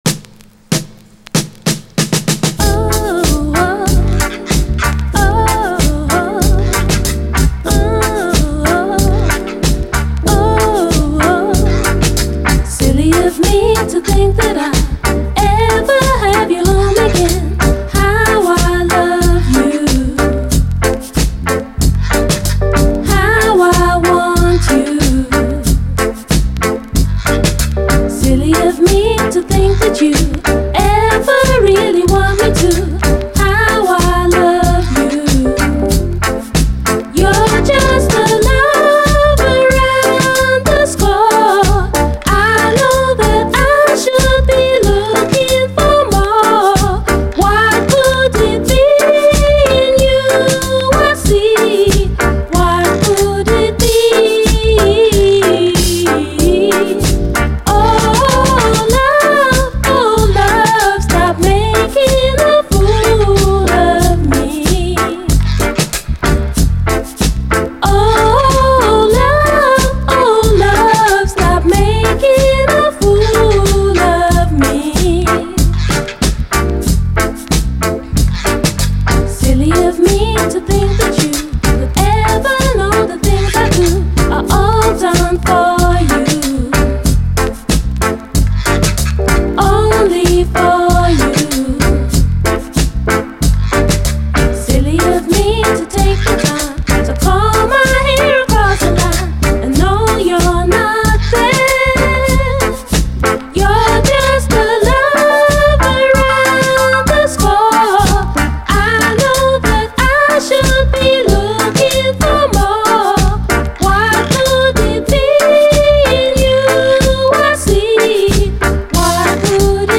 REGGAE
謎のオブスキュア・キラーUKラヴァーズ〜ディスコ・レゲエ！